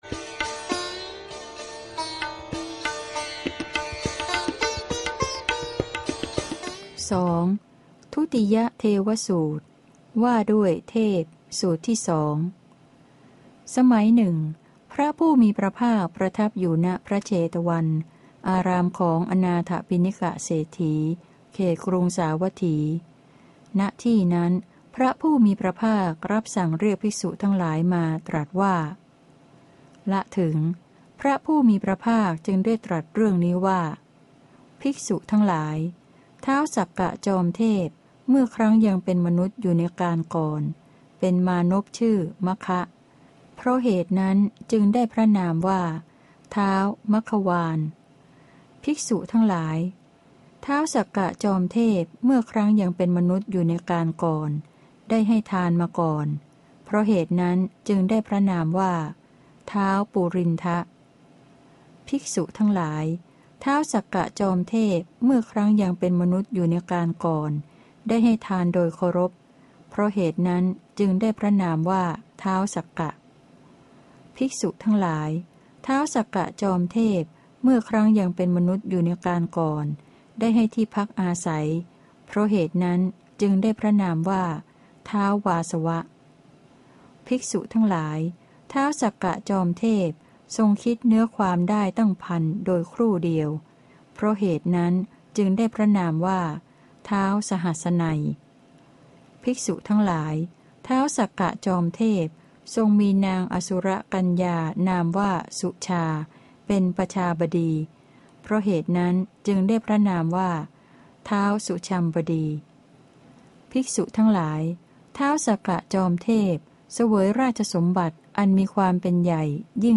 พระไตรปิฎก ภาคเสียงอ่าน ฉบับมหาจุฬาลงกรณราชวิทยาลัย - เล่มที่ ๑๕ พระสุตตันตปิฏก